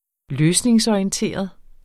Udtale [ -ɒiənˌteˀʌð ]